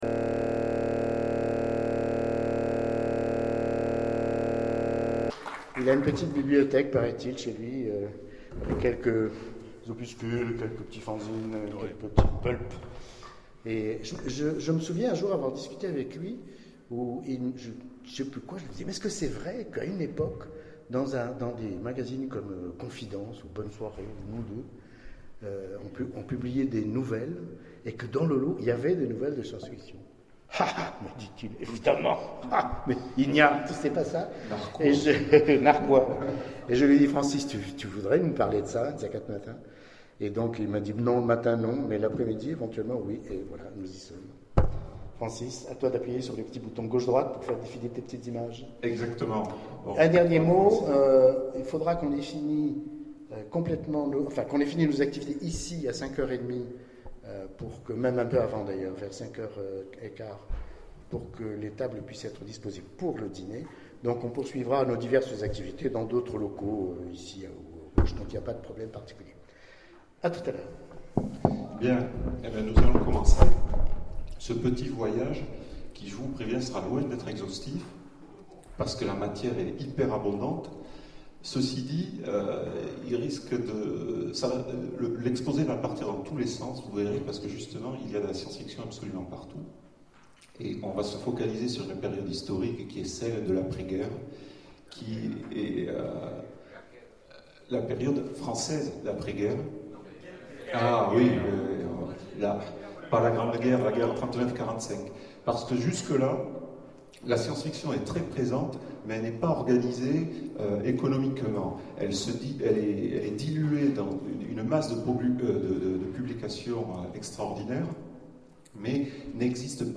Convention SF d'Aubenas - conférence La SF française hors-édition : à la recherche de la SF perdue...